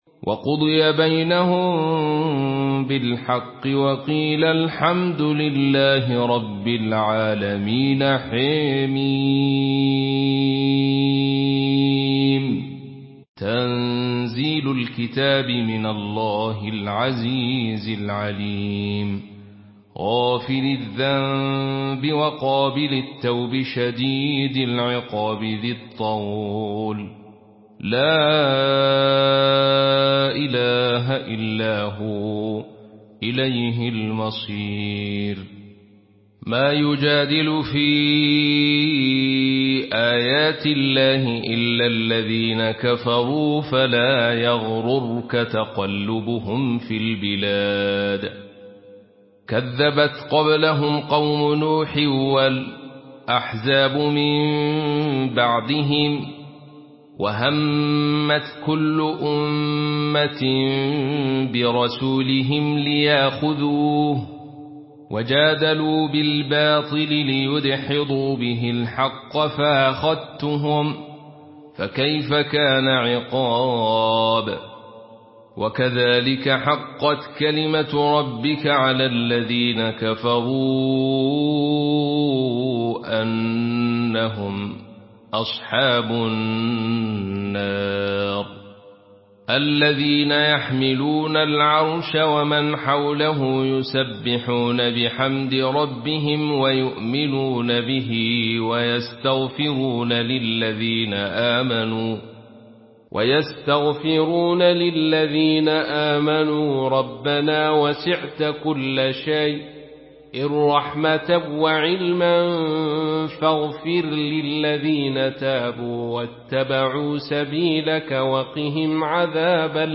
Surah Ghafir MP3 in the Voice of Abdul Rashid Sufi in Khalaf Narration
Murattal